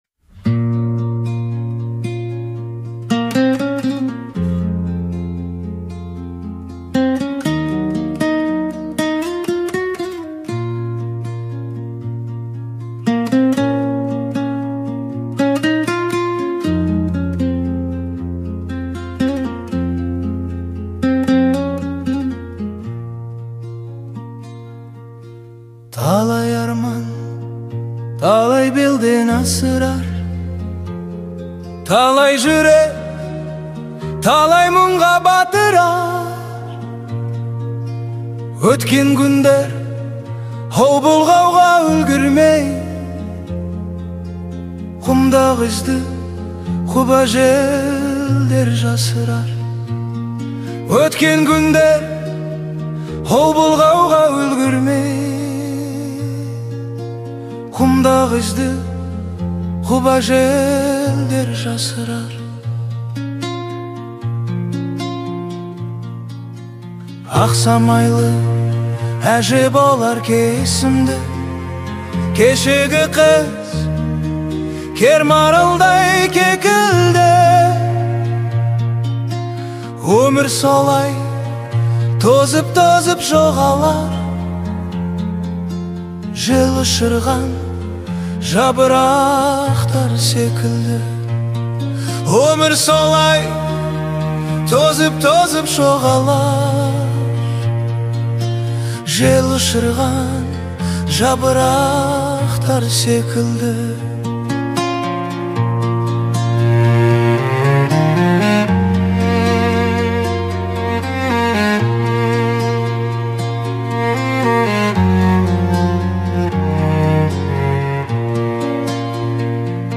Казахская музыка